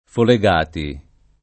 [ fole g# ti ]